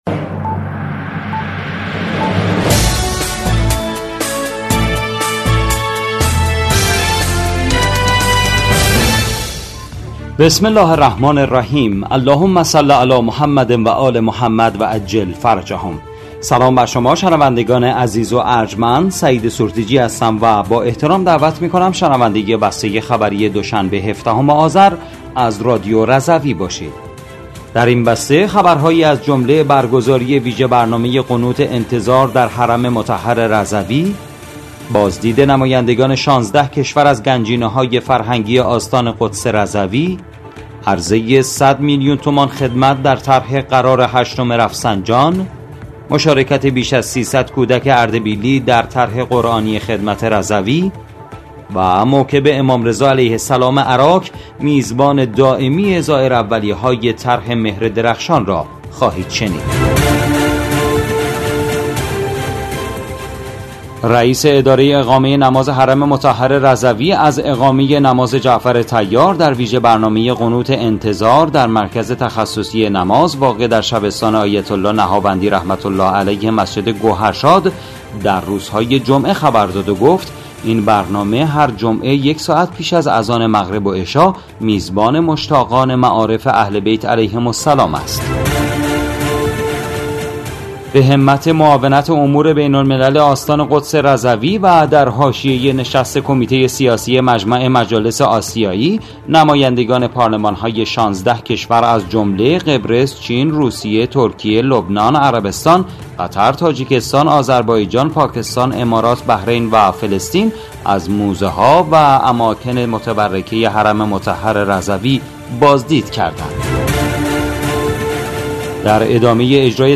بسته خبری ۱۷ آذر ۱۴۰۴ رادیو رضوی؛